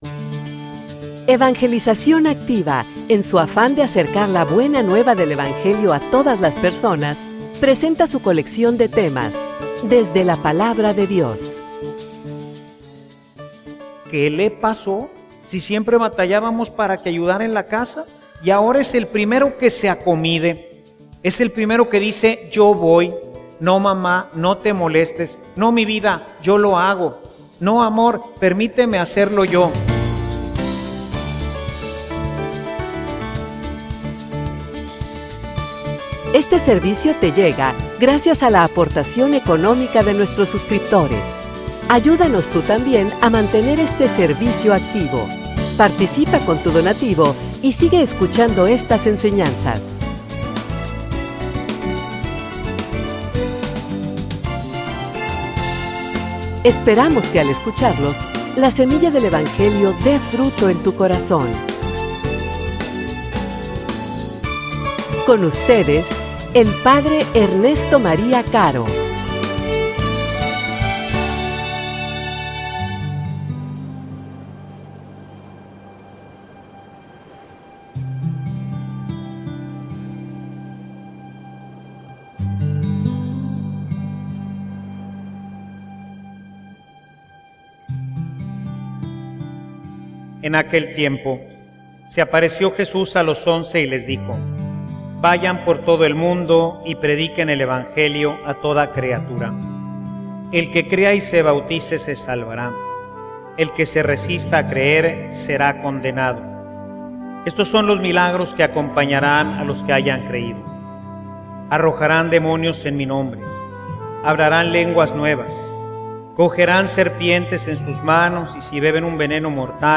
homilia_No_te_fallaremos.mp3